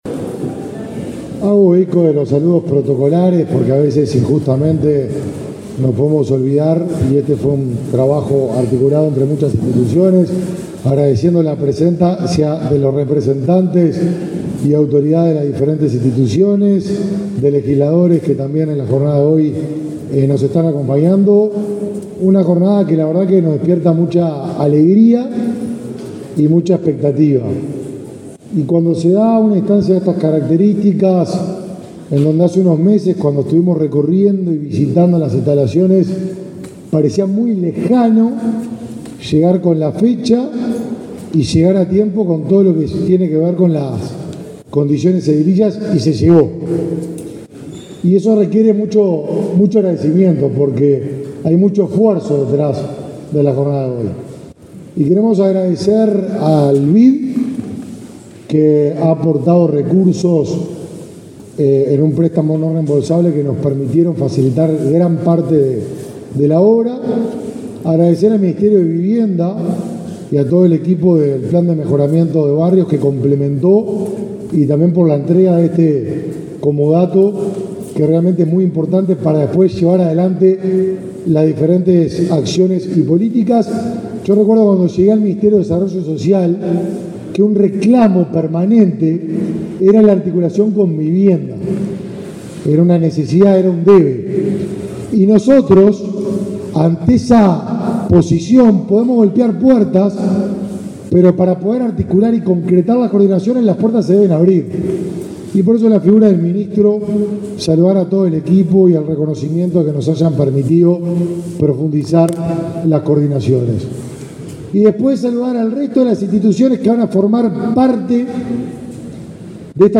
Palabras de autoridades en Centro de Encuentro para Migrantes
El ministro de Desarrollo Social, Martín Lema, y su par de Vivienda, Raúl Lozano, participaron en el acto de recepción de la obra del Centro de